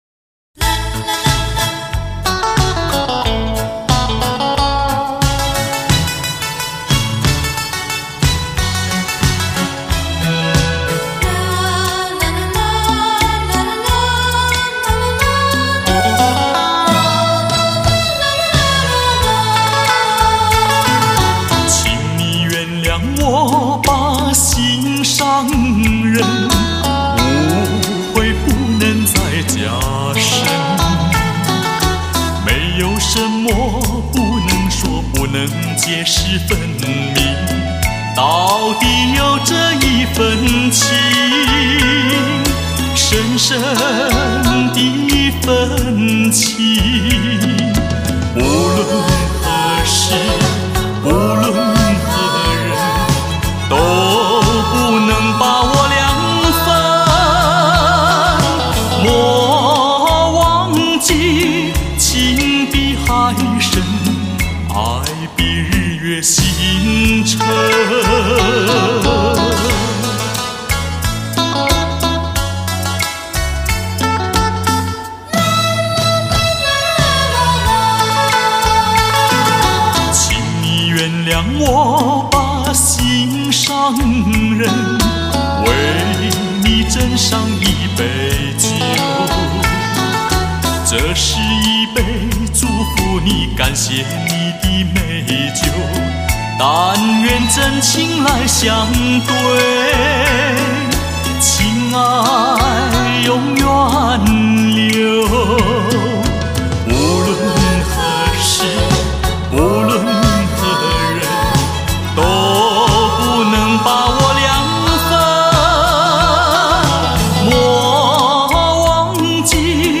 音质好!(已添加恢复3%)